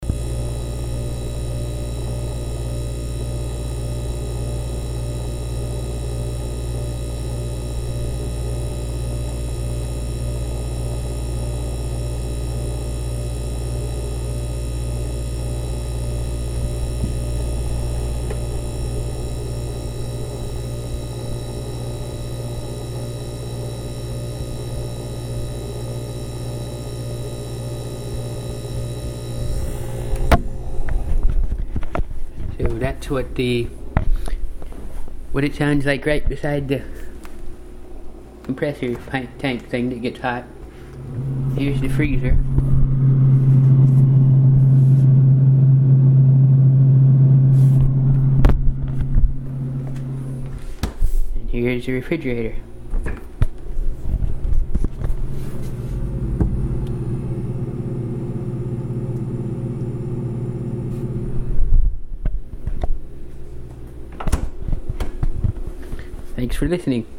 Here's a recording of my new Frigidaire 4.5 cft. refrigerator in opperation
There are lots of vent openings in the freezer and the fan as you can hear in this recording is noisy in there.
It's a bit loud, even louder than my old one is after it got louder than it used to be, but since it doesn't run nearly as much of the time I'm still pretty satisfied I think.